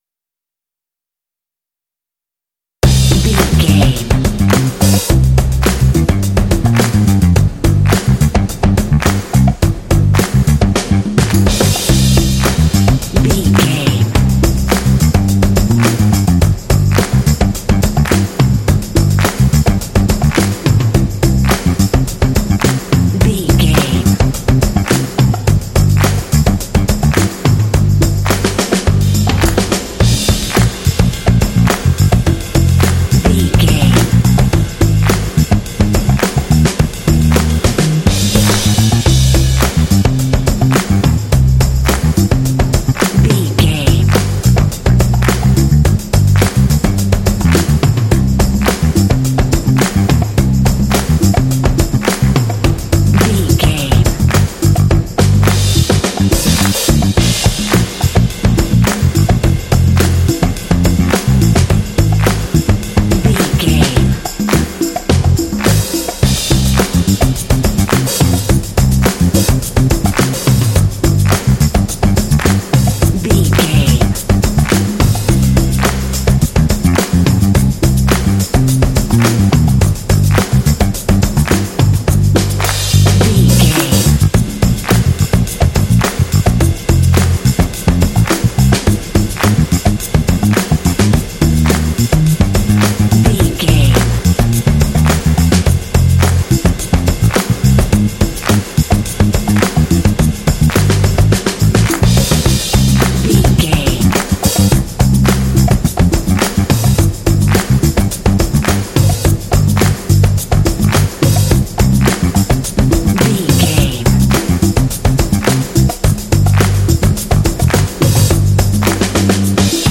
This funky track is ideal for kids and sports games.
Uplifting
Aeolian/Minor
funky
groovy
driving
energetic
lively
bass guitar
drums
percussion
Funk
alternative funk